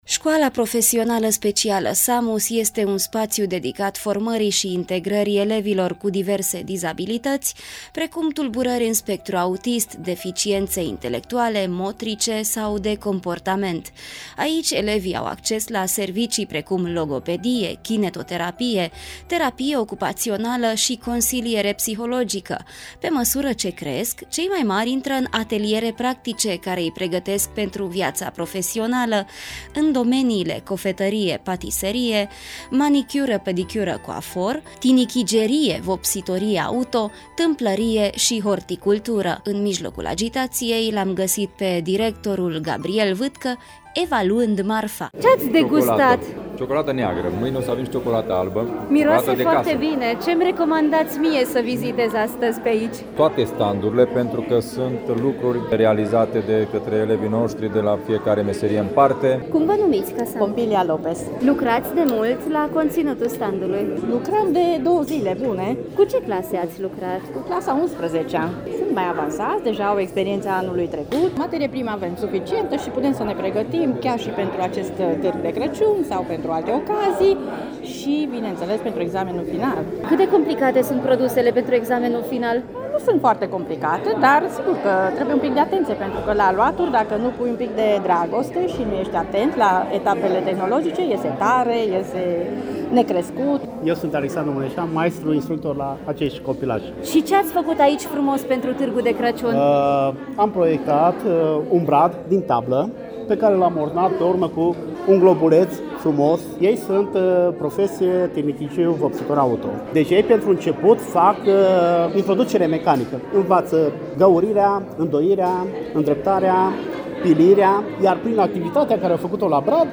Un trenuleţ, ornamente şi multă ciocolată: s-a deschis Târgul de Crăciun al Şcolii Samus | AUDIO